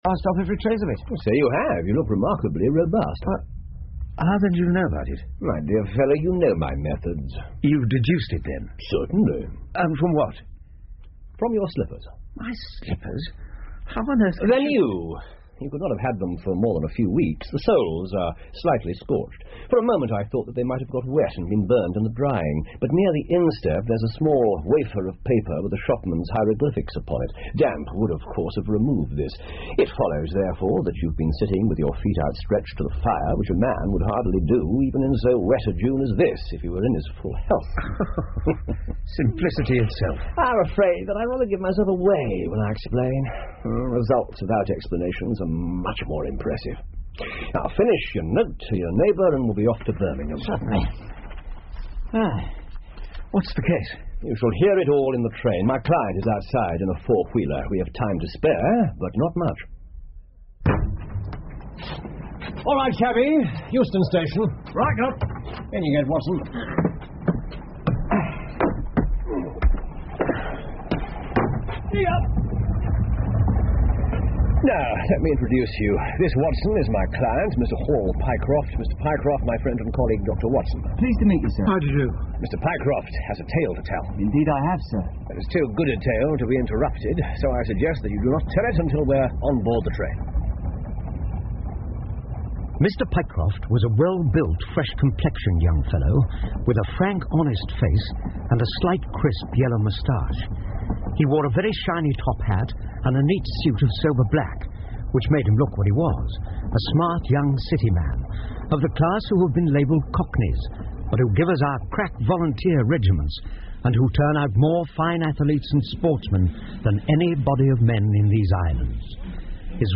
福尔摩斯广播剧 The Stock Brokers Clerk 2 听力文件下载—在线英语听力室